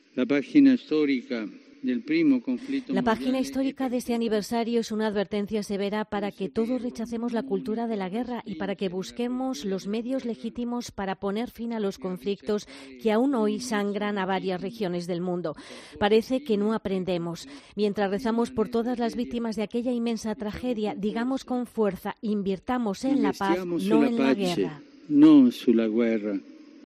"La página histórica del primer conflicto mundial es para todos un severo llamamiento a rechazar la cultura de la guerra y a buscar todos los medios legítimos para poner fin a todos los conflictos que aún ensangrentan muchas regiones del mundo", dijo tras el Ángelus.
"Digamos con fuerza: ¡Invirtamos en la paz, no en la guerra!", exclamó Francisco desde la ventana del Palacio Apostólico ante los fieles que escuchaban en la plaza de San Pedro, después de asegurar sus oraciones por las víctimas "de aquella enorme tragedia".